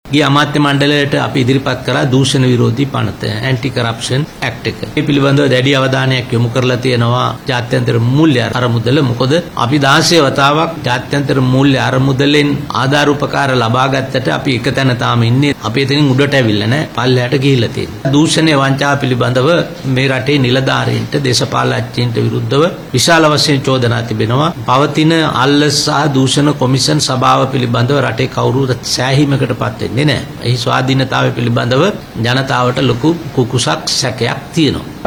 කොළඹ අද පැවති මාධ්‍ය හමුවකට එක්වෙමින් අධිකරණ අමාත්‍යවරයා මේ බව ප්‍රකාශ කළා.